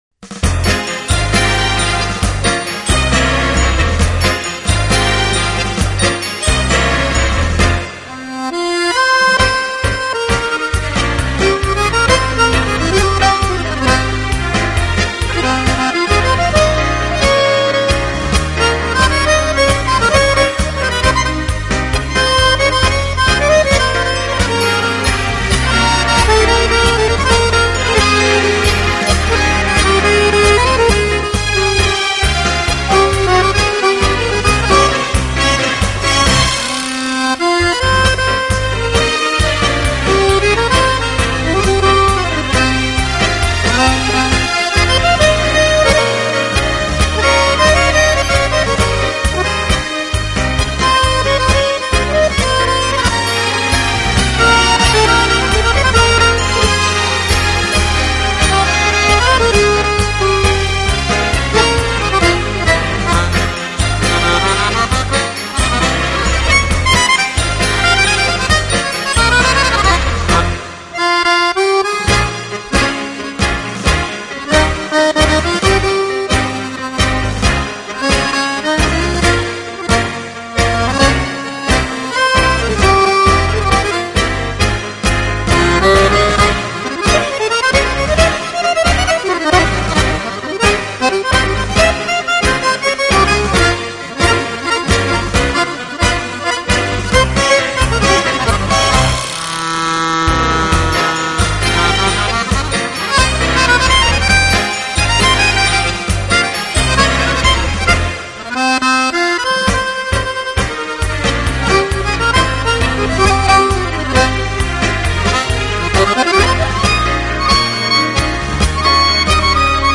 音乐类型: Pop, Instrumental Accordeon